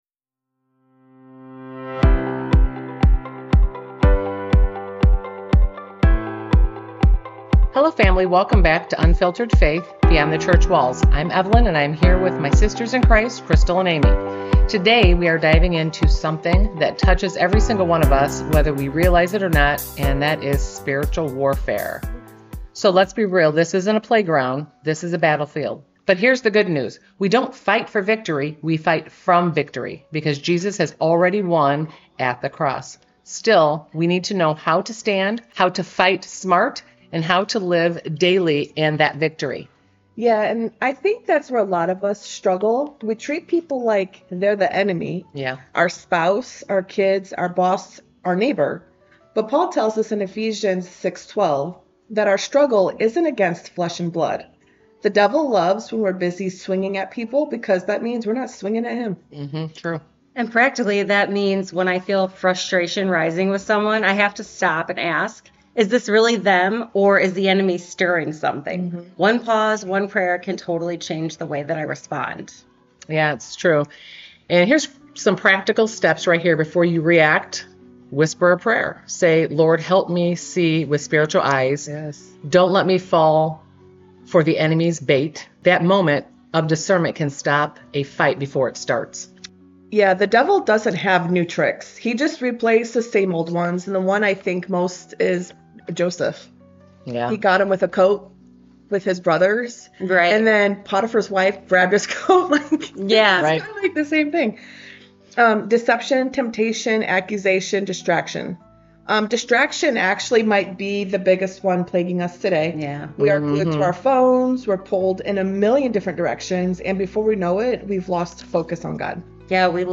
With raw stories, practical tools, and Scripture-based strategies, this conversation is both real and empowering.
Disclaimer: We’re not experts—just Jesus-loving women sharing our faith, experiences, and Scripture.